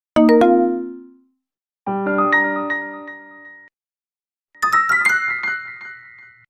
sounds familiar? sounds with simple sound effects free download
sounds familiar? sounds with simple piano